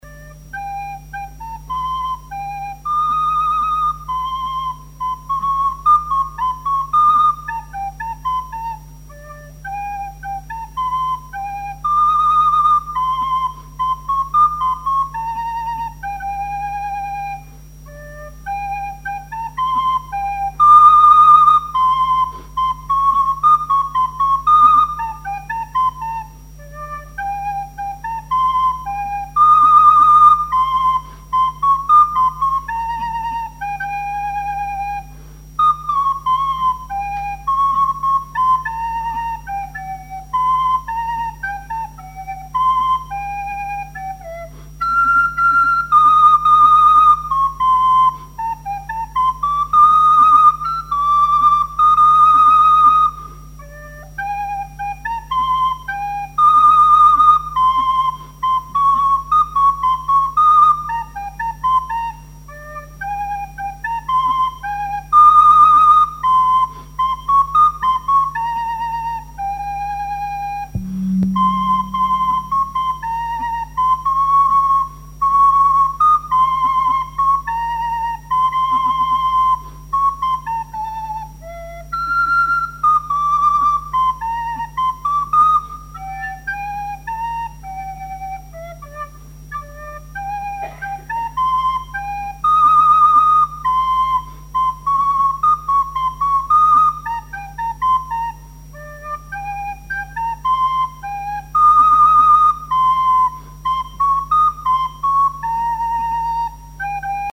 collectif de musiciens pour une animation à Sigournais
Pièce musicale inédite